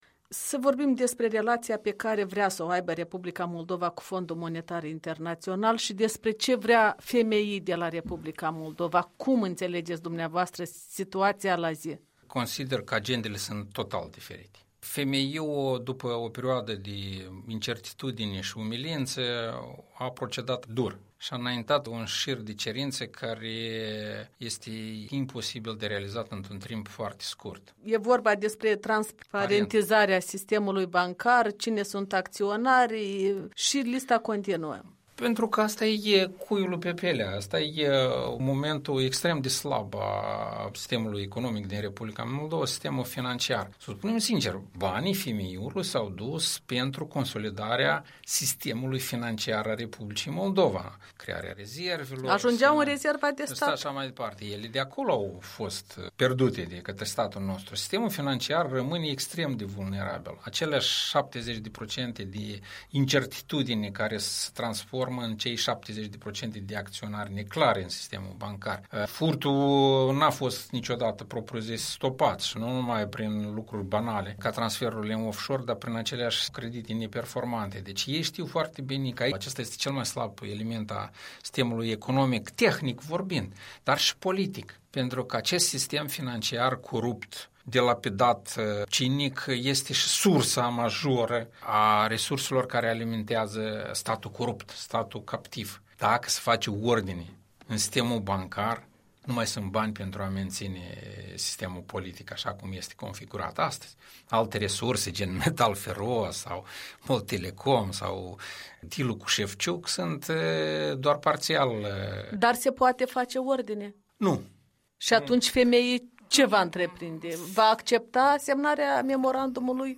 Interviurile Europei Libere: Ion Sturza